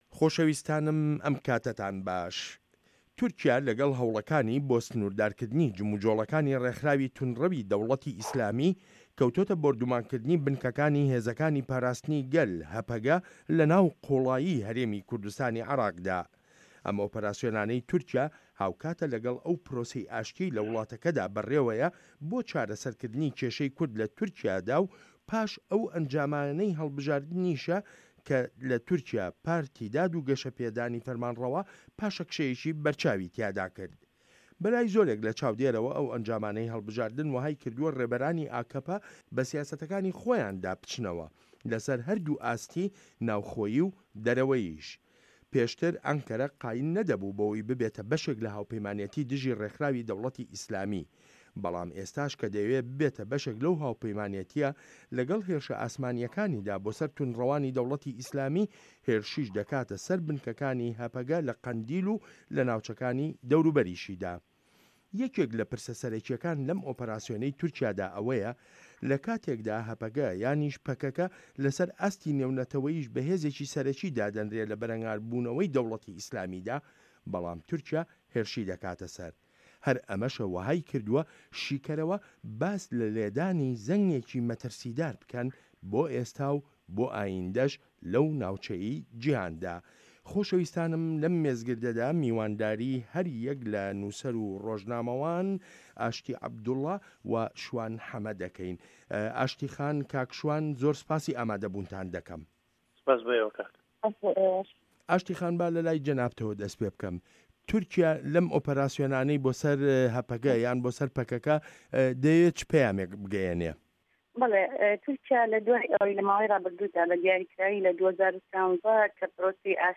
مێزگرد: په‌یامی تورکیا له‌ ئۆپه‌راسیۆنه‌کانیدا